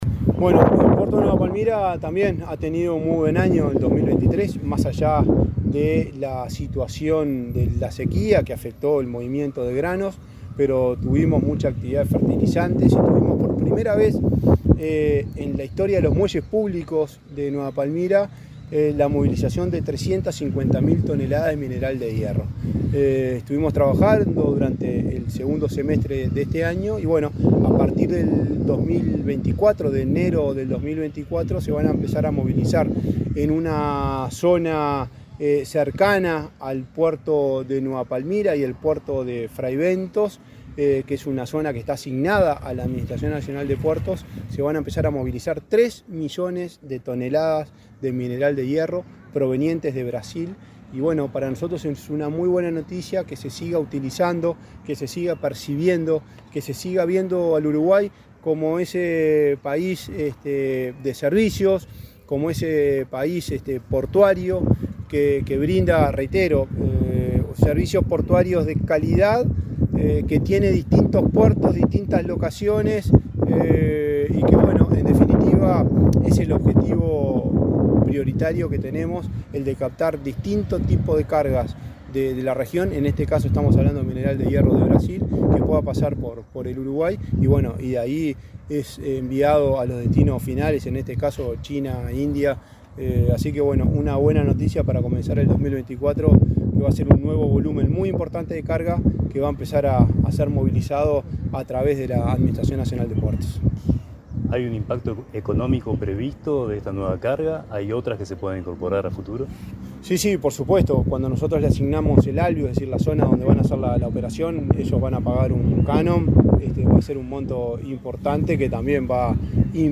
Entrevista al presidente de la ANP, Juan Curbelo
El presidente de la Administración Nacional de Puertos (ANP), Juan Curbelo, dialogó con Comunicación Presidencial en Piriápolis, departamento de